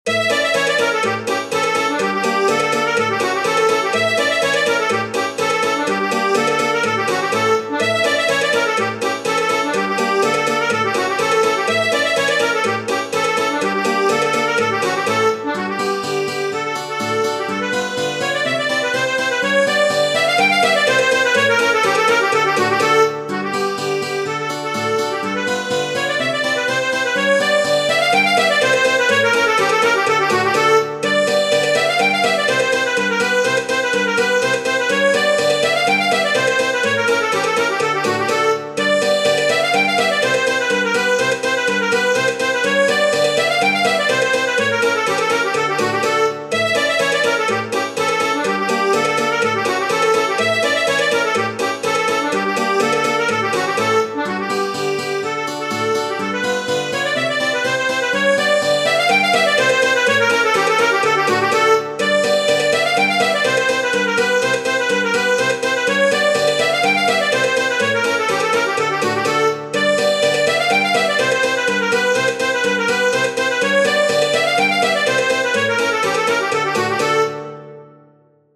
Tradizionale Genere: Folk "Hora de la Teis", noto anche come "Hora lui gore" e "Hora Victor", è un brano appartenente al repertorio della musica folk rumena.